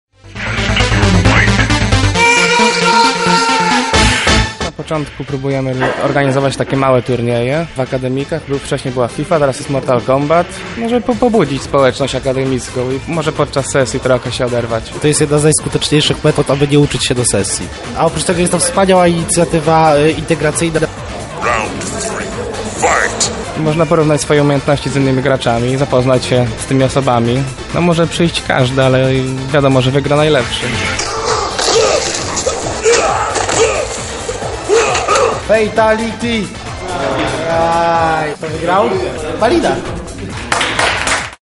Wczoraj w DS UMCS Femina rozegrano Turniej Mortal Kombat – Battle of Dormitory.
Turniej-Mortal-Kombat-relacja.mp3